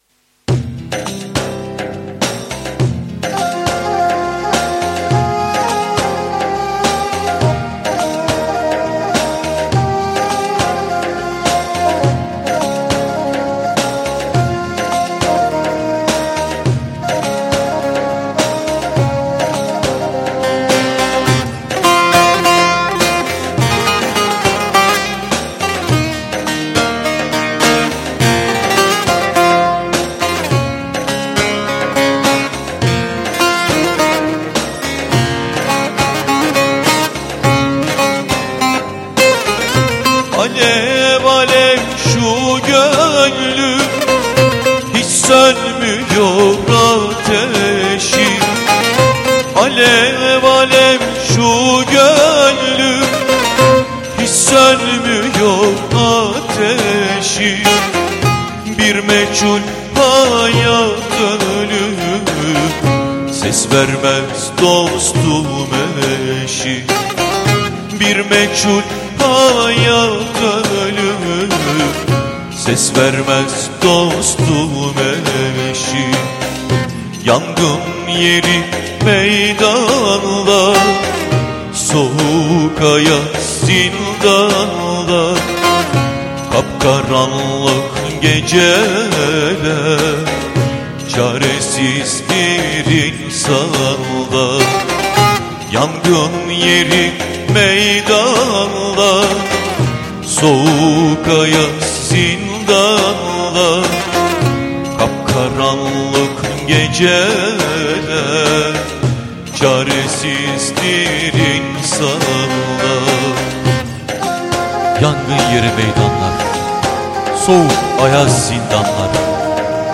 Düzenleme&Solist